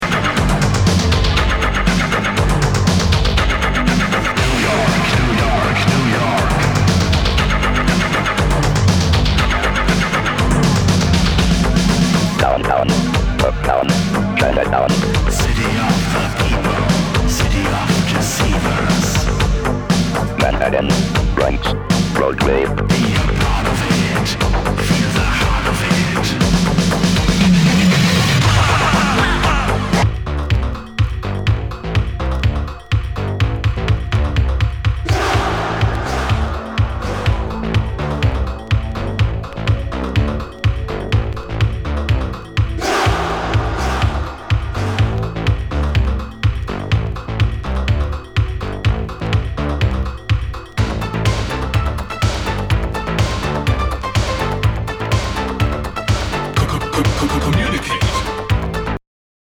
HOUSE/TECHNO/ELECTRO
ナイス！！エレクトロ / シンセ・ポップ！！